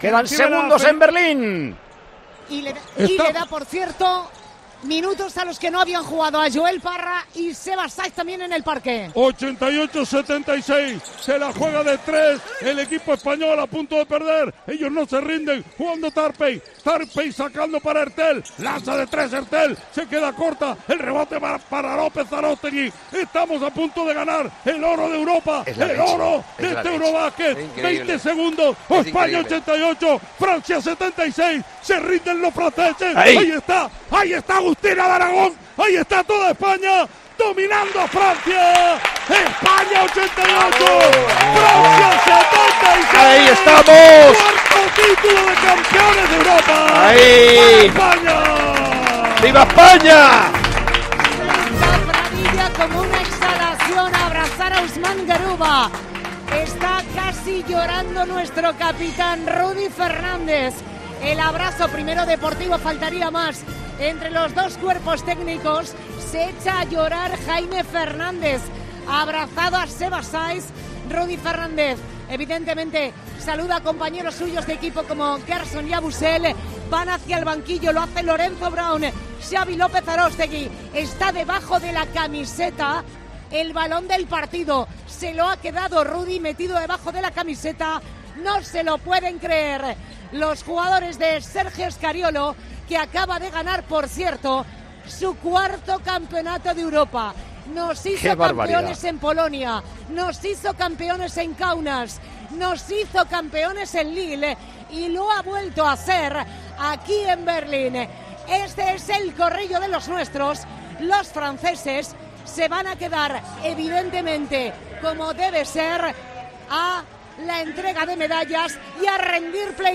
Así hemos vivido en Tiempo de Juego el triunfo de España en la final del Eurobasket ante Francia